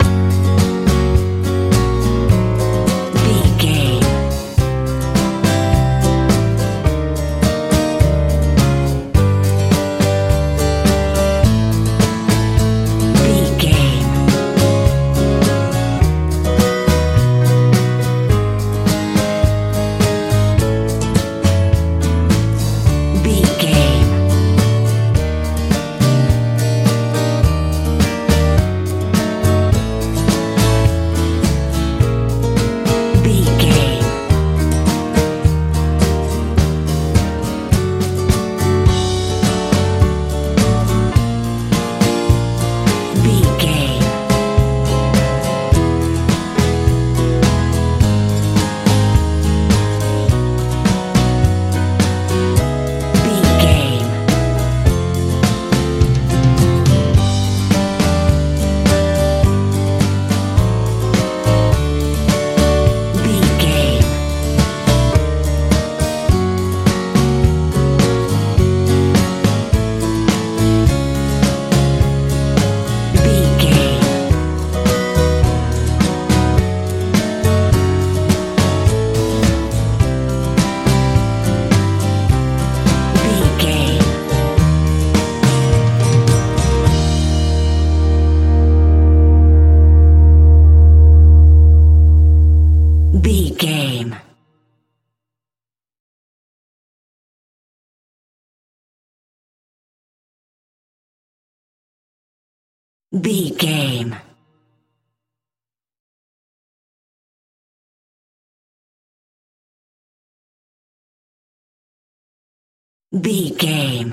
lite pop feel
Ionian/Major
F♯
groovy
soft
piano
acoustic guitar
drums
bass guitar
sweet
joyful